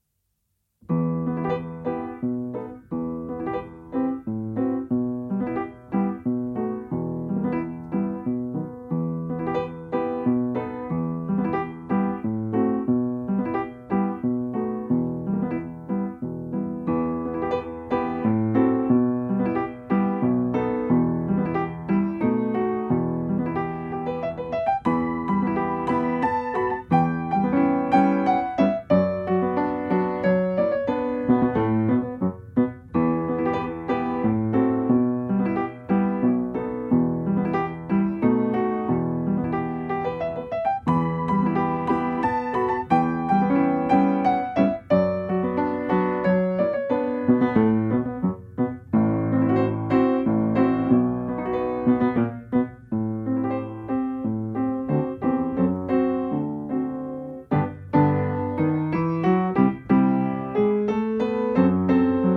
akompaniamentu pianina
Strój 440Hz
I wersja – wirtuozowska
Tempo: 90 bmp
Nagrane z metronomem.
Nagranie uwzględnia powtórkę.
Nagranie uwzględnia zwolnienia.